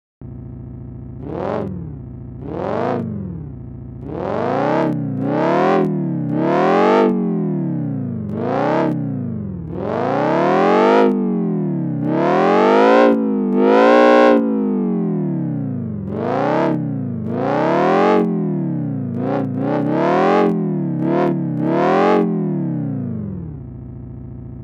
It's a relatively synthetic engine sound. It starts off as a low, muted hum. I apply the throttle, at which point the filters open up, the distortion increases, and the oscillators increase in pitch.
so made a little engine sound experiment using Web Audio API. 7 Oscillators, a wave shaper, and two filters.